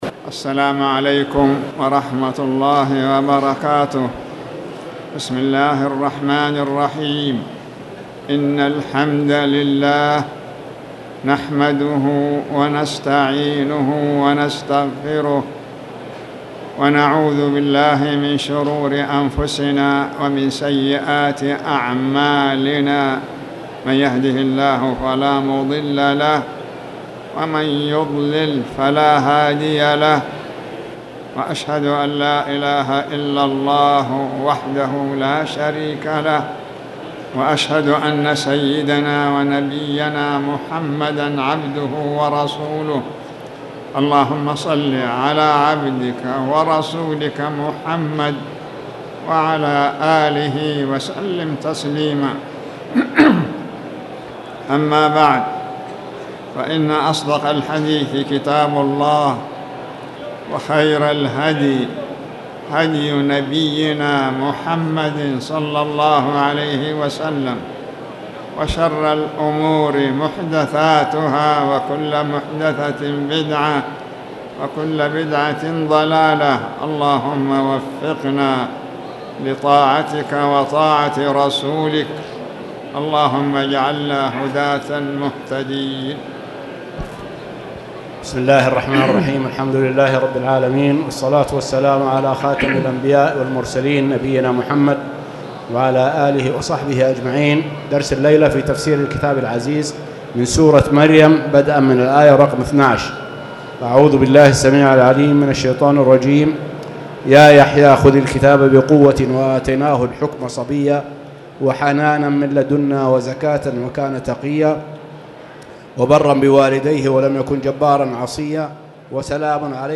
تاريخ النشر ١٥ ربيع الأول ١٤٣٨ هـ المكان: المسجد الحرام الشيخ